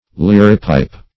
liripipe - definition of liripipe - synonyms, pronunciation, spelling from Free Dictionary Search Result for " liripipe" : The Collaborative International Dictionary of English v.0.48: Liripipe \Lir"i*pipe\ (l[i^]r"[i^]*p[imac]p), n. [Obs.]
liripipe.mp3